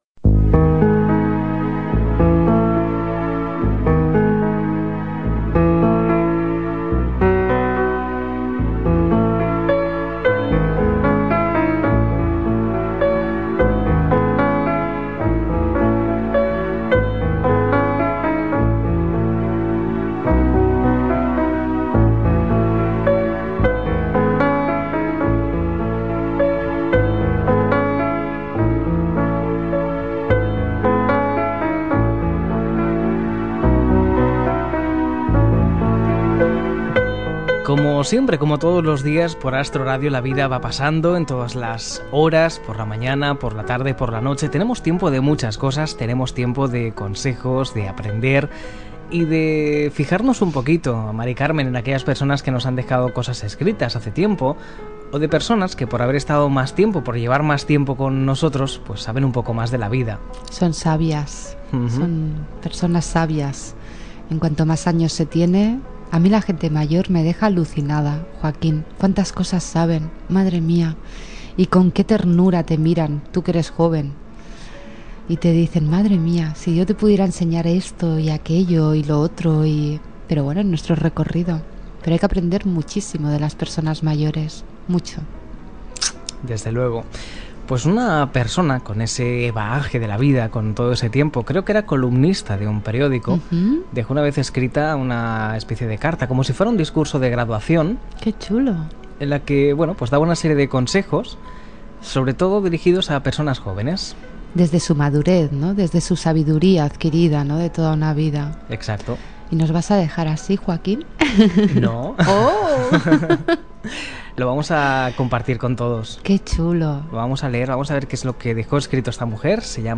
Presentació del programa amb la lectura del discurs de graduació
Banda FM